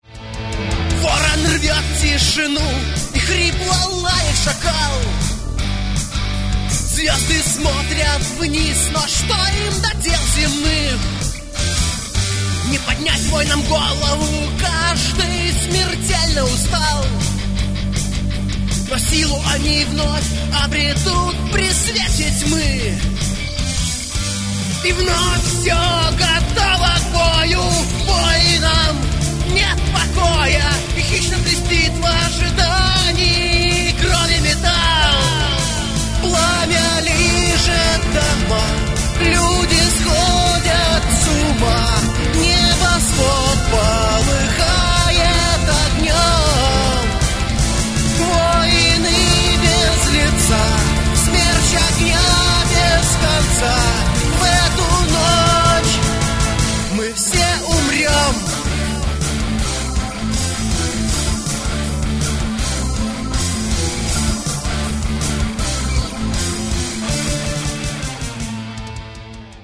Гитары, бас, клавиши, перкуссия, вокал
фрагмент (410 k) - mono, 48 kbps, 44 kHz